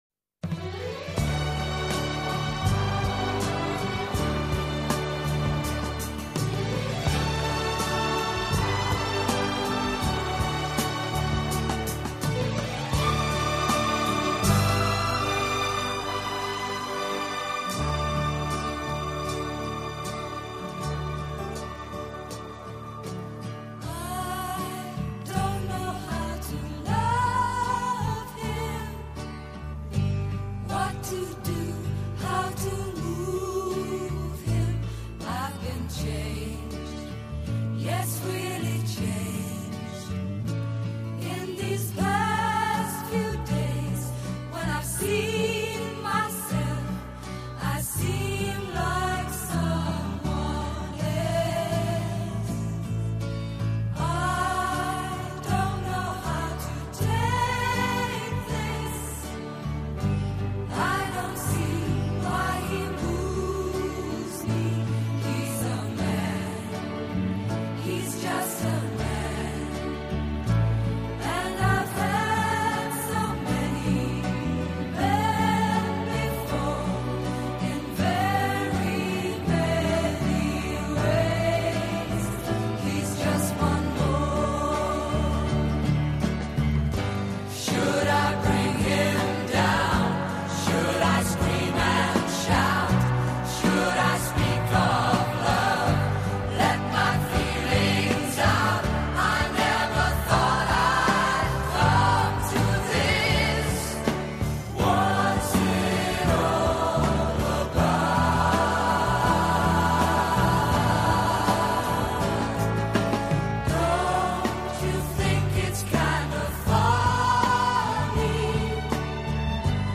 世界三大轻音乐团